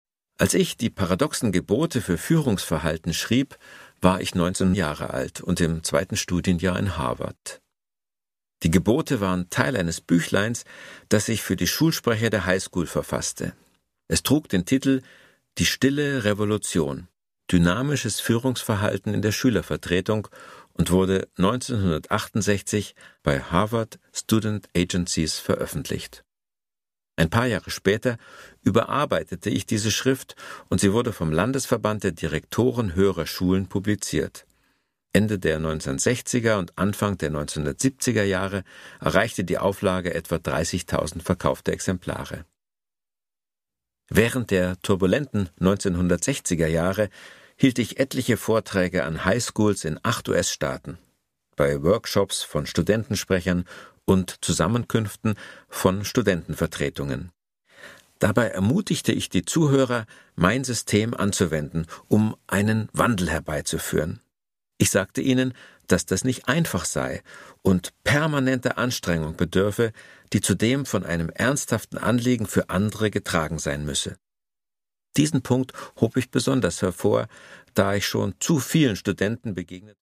Produkttyp: Hörbuch-Download
Fassung: Autorisierte Lesefassung
Gelesen von: Werner Tiki Küstenmacher